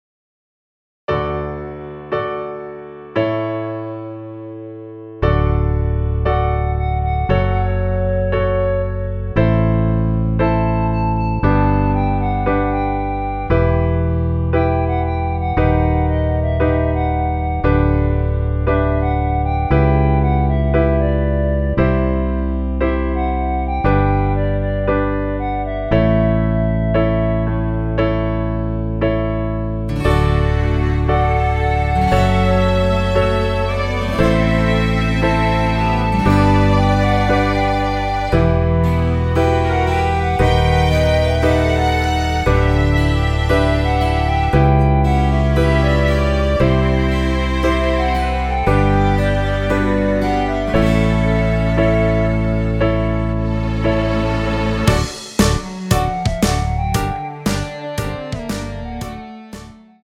원키에서(+5)올린 멜로디 포함된 MR입니다.(미리듣기 확인)
앞부분30초, 뒷부분30초씩 편집해서 올려 드리고 있습니다.